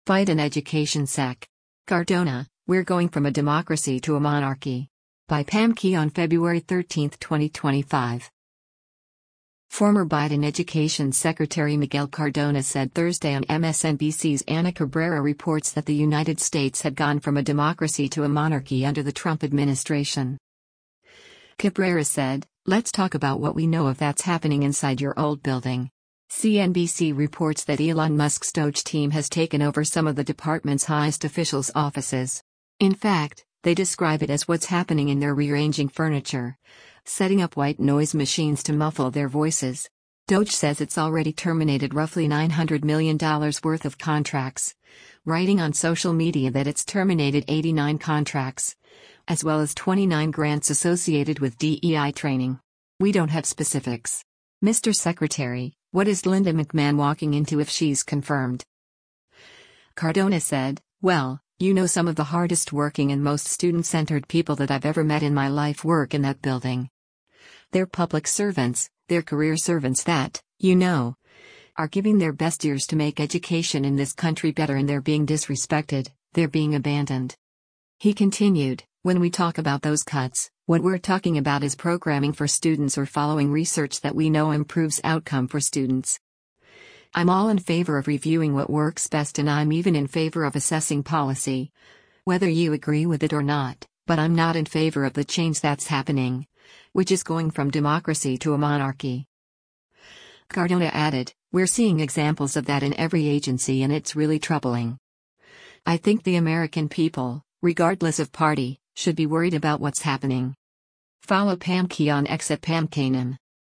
Former Biden Education Secretary Miguel Cardona said Thursday on MSNBC’s “Ana Cabrera Reports” that the United States had gone from a democracy to a monarchy under the Trump administration.